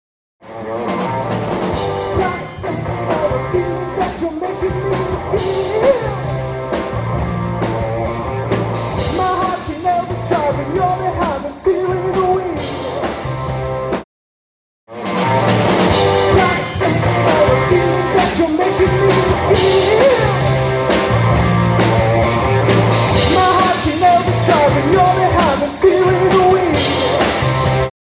Can you help me EQ/process my live MP3 recording?
I recorded my band’s live show on my 1-track Zoom H2 in 128kb mp3 mode.